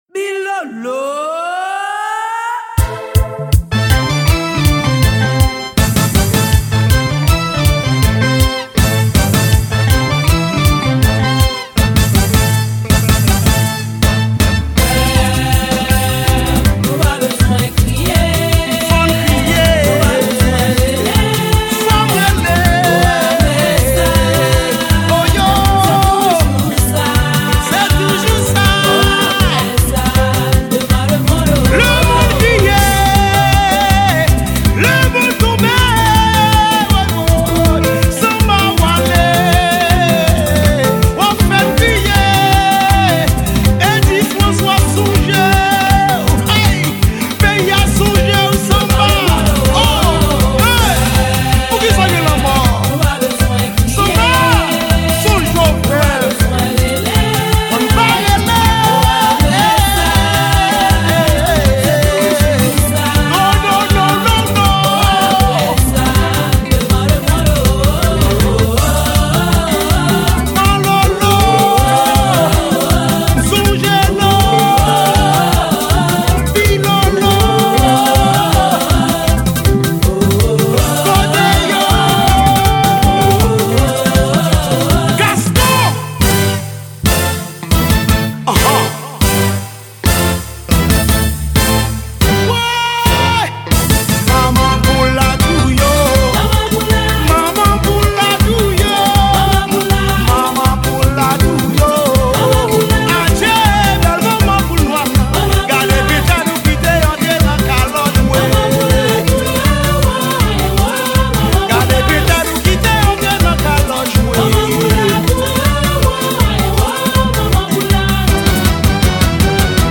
Genre: Rasin.